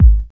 SOULFUL.wav